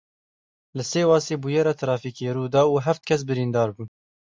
Pronounced as (IPA) /ħæft/